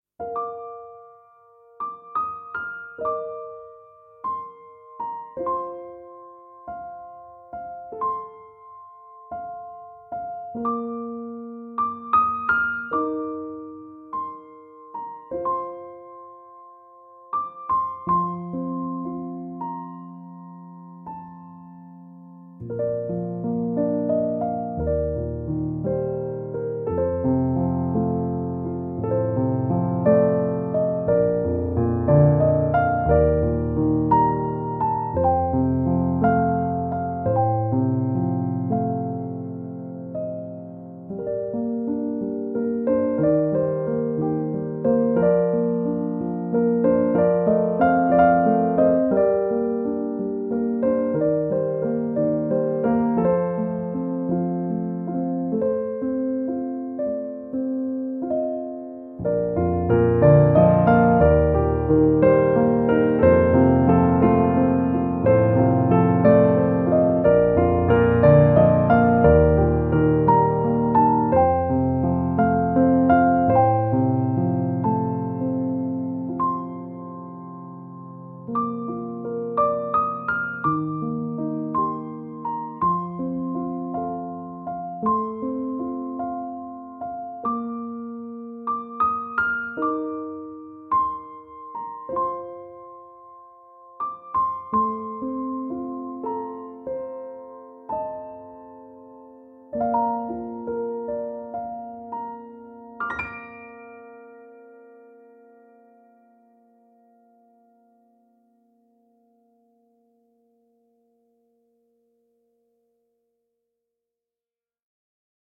Piano Solo
Voicing/Instrumentation: Piano Solo